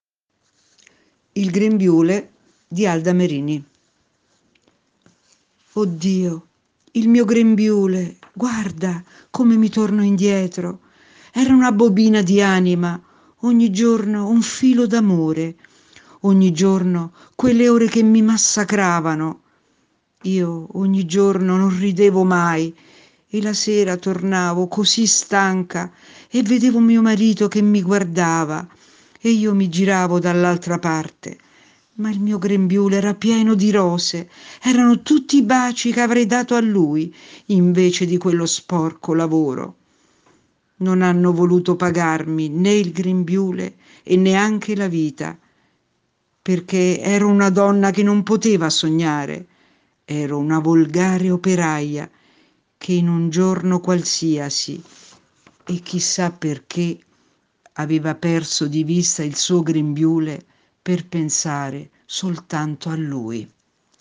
/ Lettura ad Alta Voce